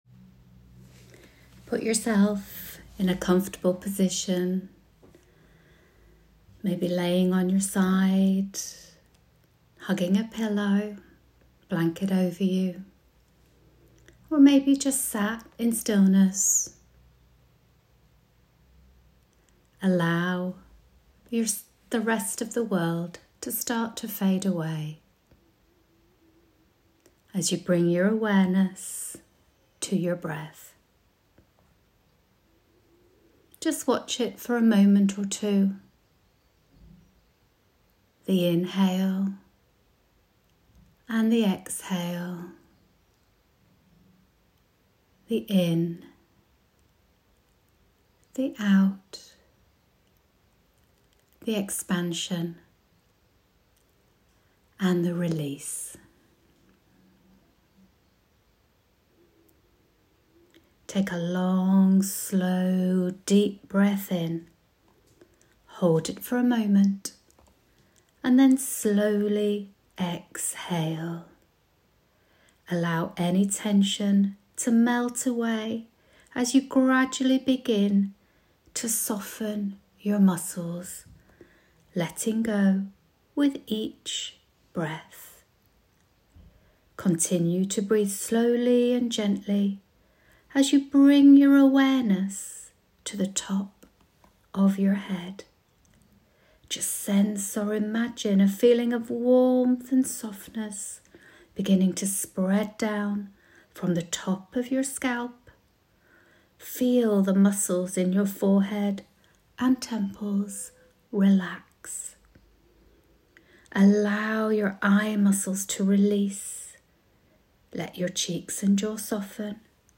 Relaxation Nidra